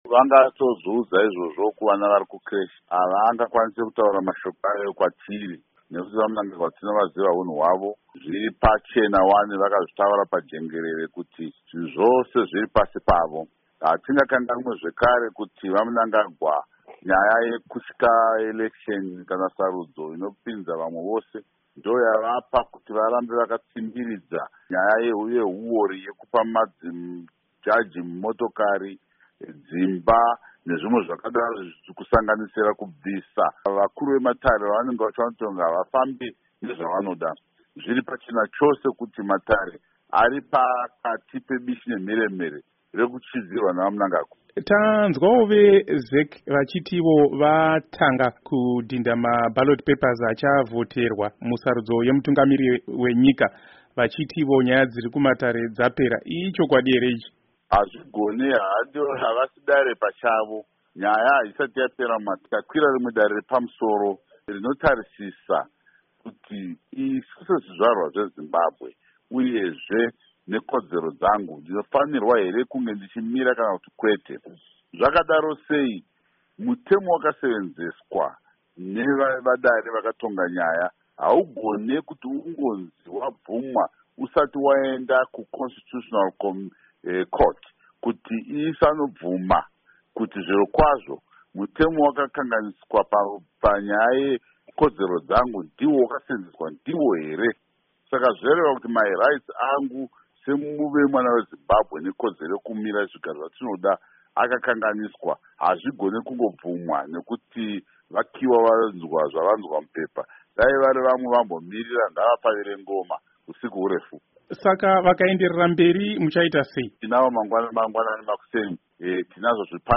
Hurukuro naVaSaviour Kasukuwere